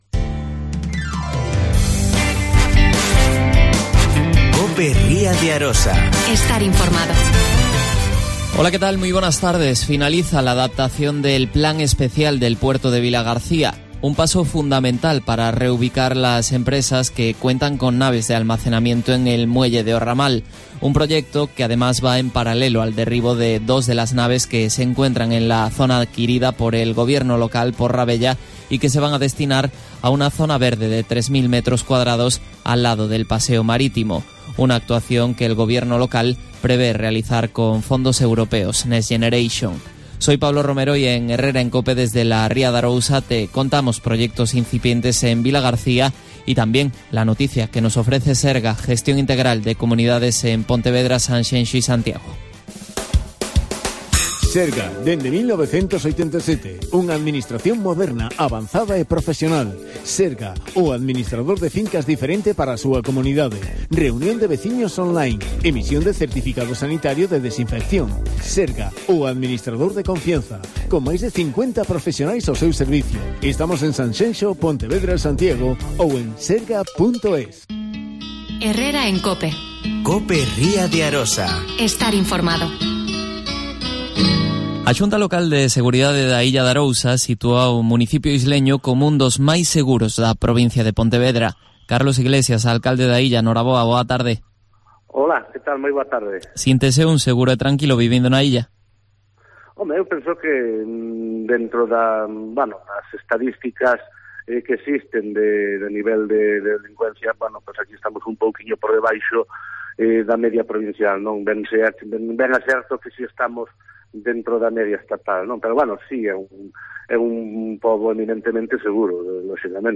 AUDIO: Carlos Iglesias. Alcalde da Illa de Arousa.